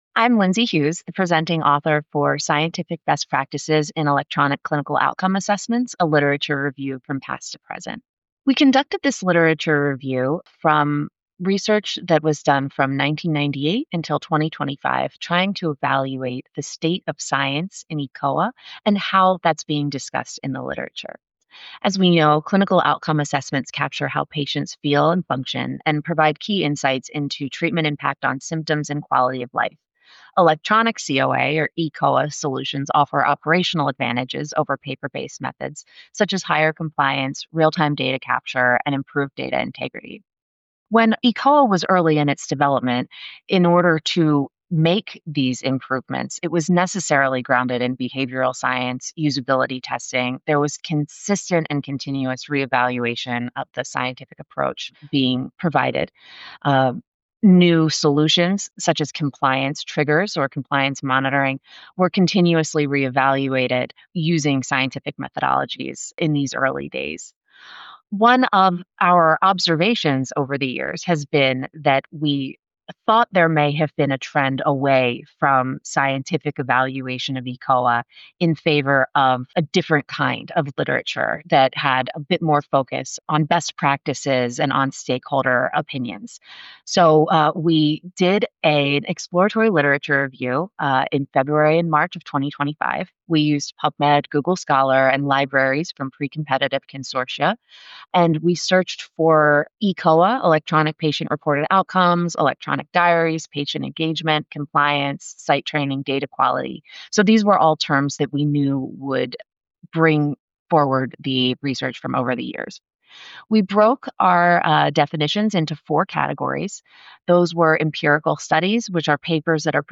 she provides an overview of the poster and key takeaways.